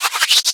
Cri de Sovkipou dans Pokémon Soleil et Lune.